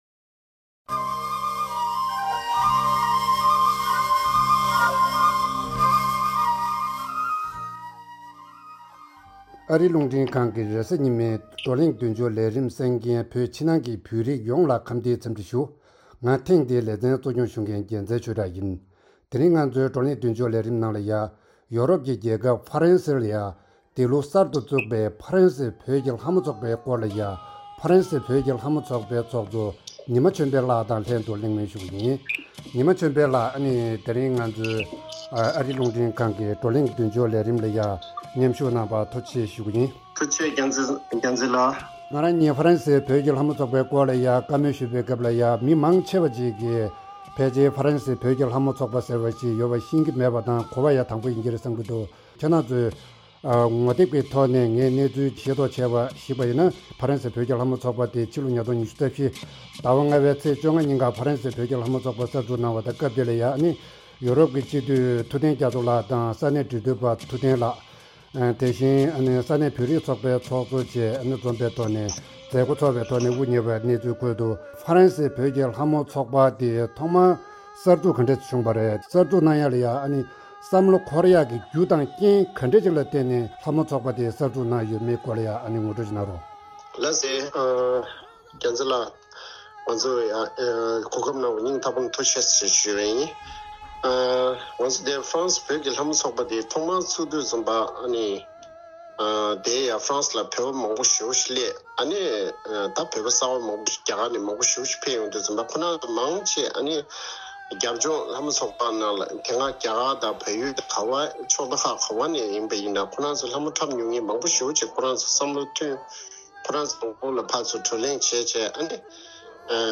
གླེང་མོལ་ཞུས་ཡོད།